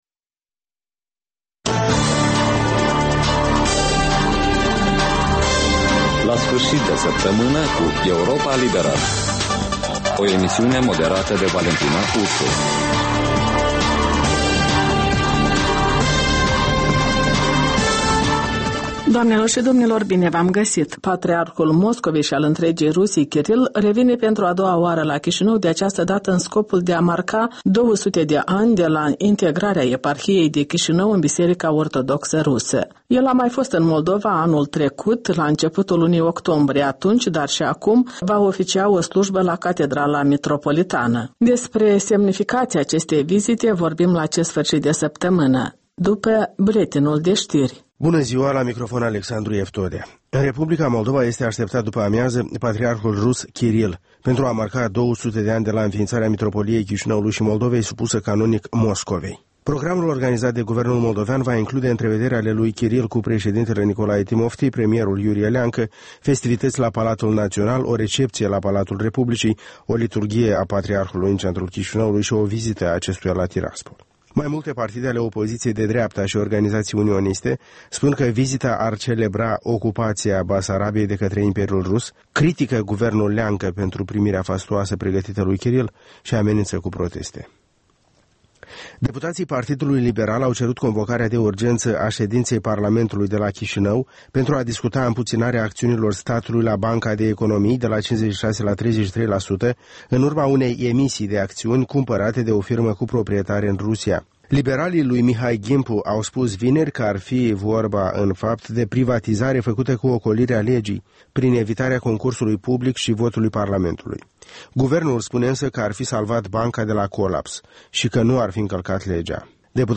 reportaje, interviuri, voci din ţară despre una din temele de actualitate ale săptămînii. In fiecare sîmbătă, un invitat al Europei Libere semneaza „Jurnalul săptămînal”.